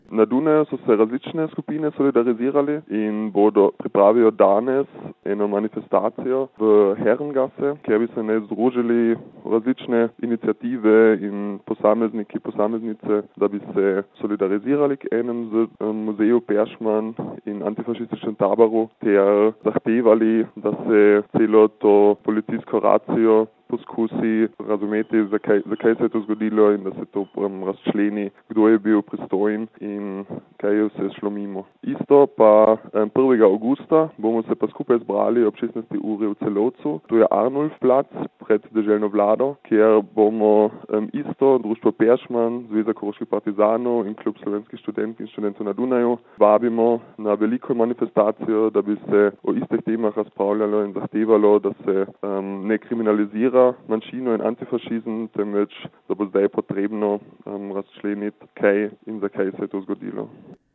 eden od študentov, ki je odmevno racijo doživel na lastni koži